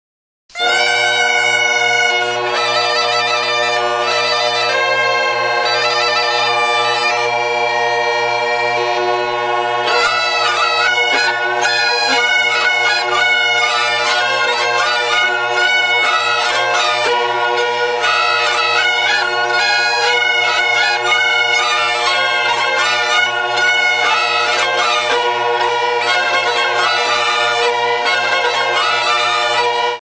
Młodzieżowy Zespół Folklorystyczny "Młodzi Biskupianie"
Młodzi Biskupianie wykonują tradycyjną muzykę biskupiańską oraz śpiewają utwory inspirowane tym folklorem.
Grają na skrzypkach i dudach.